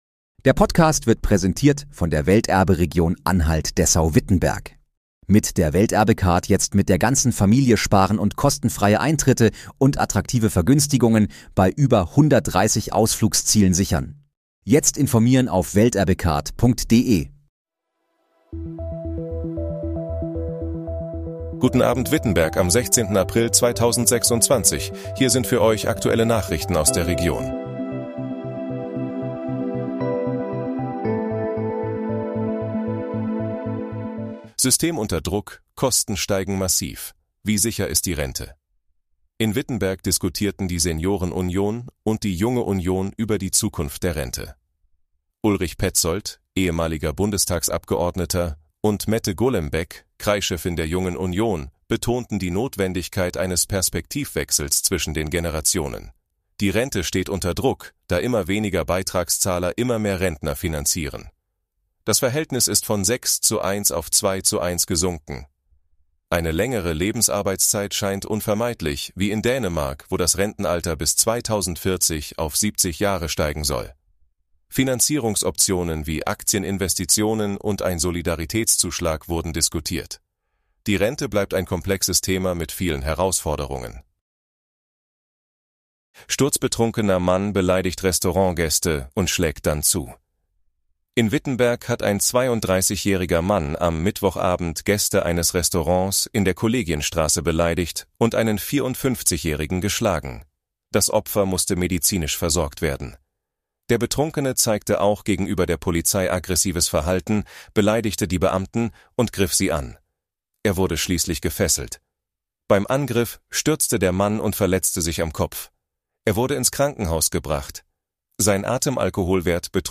Guten Abend, Wittenberg: Aktuelle Nachrichten vom 16.04.2026, erstellt mit KI-Unterstützung